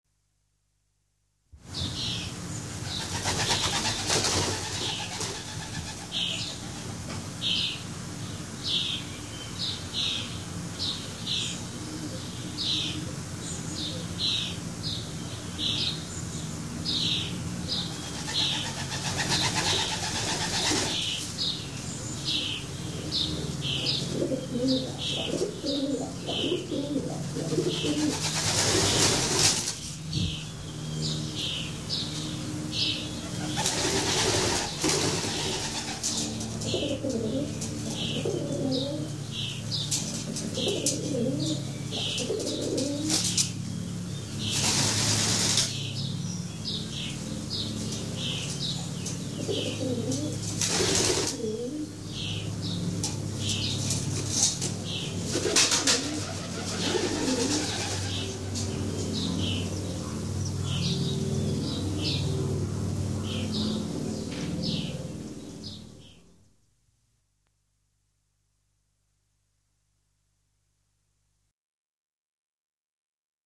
Pianeta Gratis - Audio - Animali
uccelli_birds07.mp3